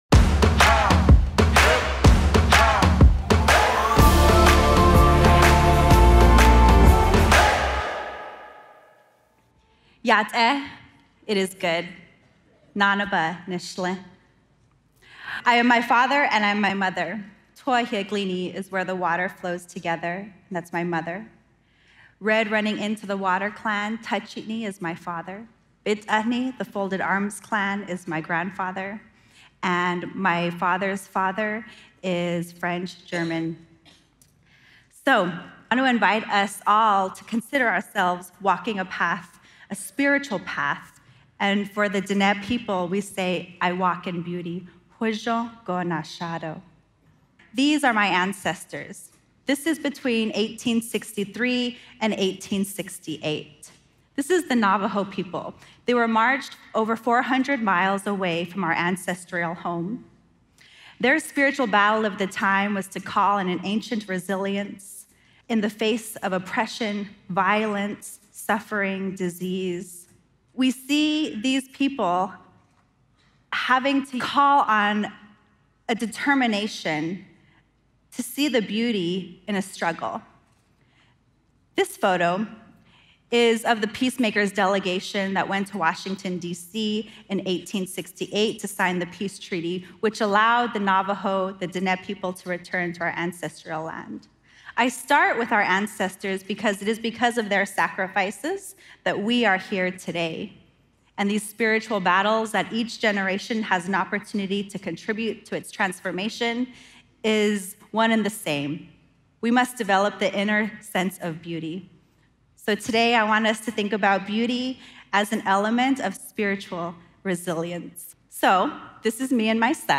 I deeply love this talk!